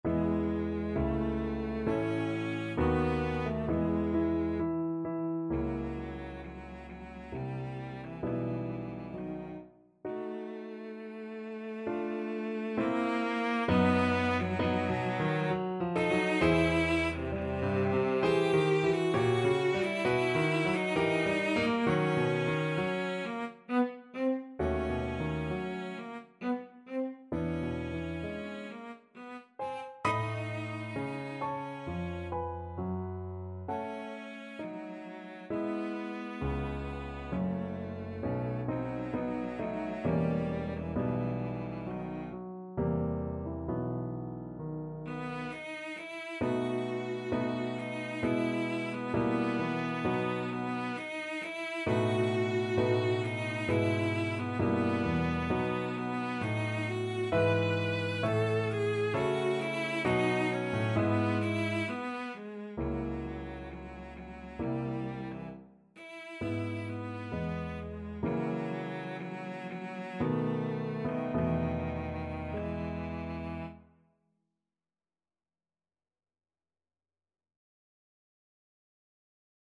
Andante Sostenuto, Main Theme Cello version
E major (Sounding Pitch) (View more E major Music for Cello )
3/4 (View more 3/4 Music)
=66 Andante sostenuto
C4-B5
Cello  (View more Intermediate Cello Music)
Classical (View more Classical Cello Music)